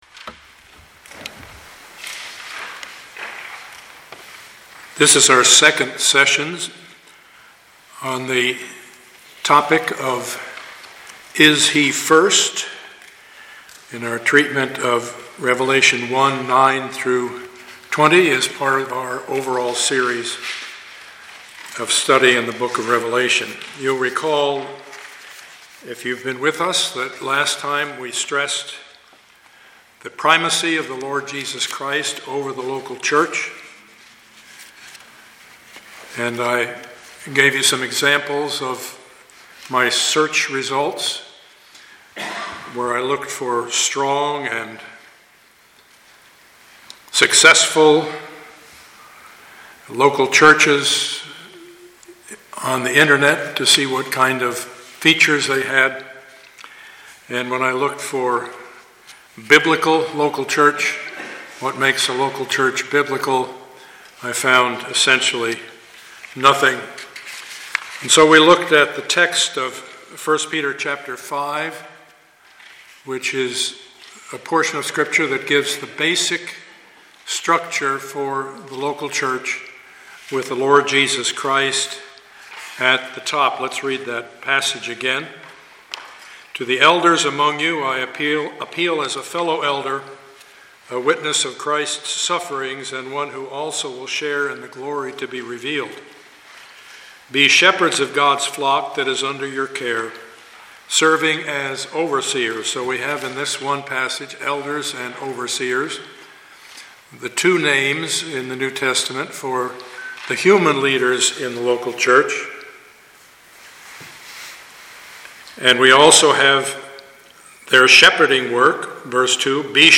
Studies in the Book of Revelation Passage: Revelation 1:9-20 Service Type: Sunday morning Part 4B of the Series « Studies in the Book of Revelation #4A